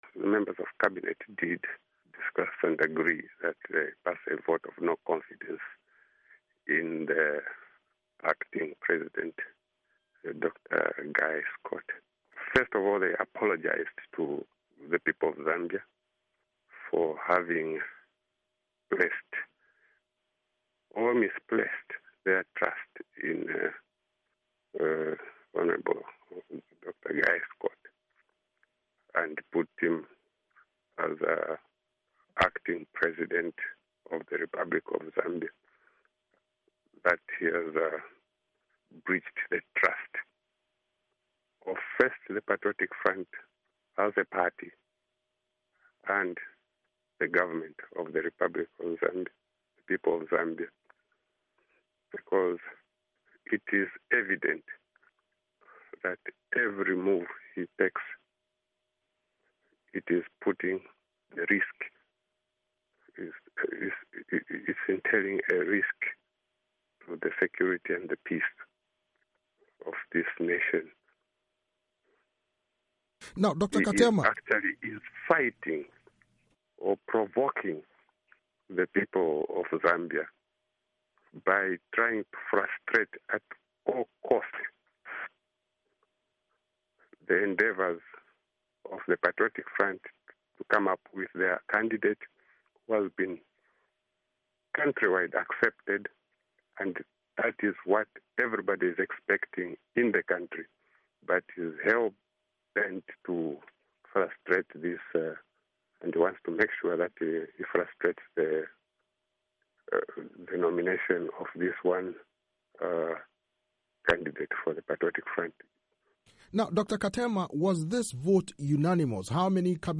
interview
Joseph Katema, Zambia's Information Minister